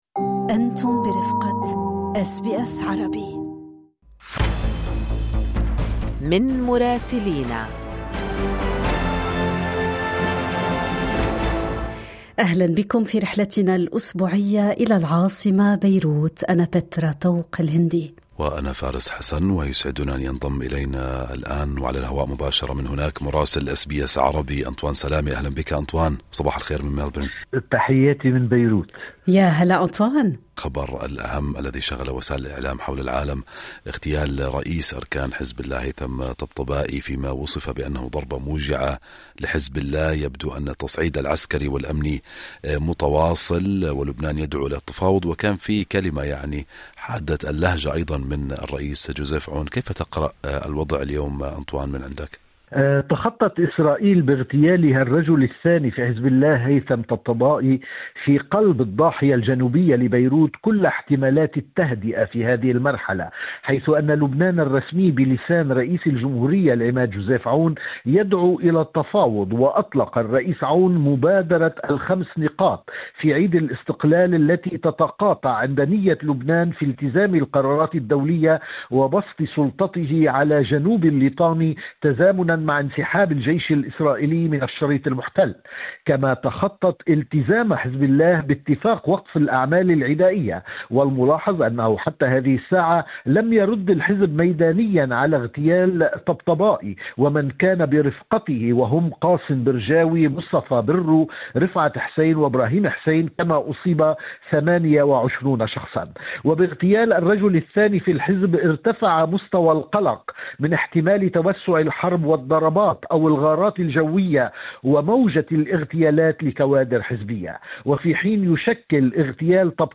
وفي ظل هذه التوترات المتصاعدة، تتداول الأوساط البيروتية أخباراً عن احتمال تأجيل زيارة البابا لاوون الرابع عشر، بما يعكس عمق القلق الدولي من هشاشة الوضع الأمني. التفاصيل مع مراسلنا في بيروت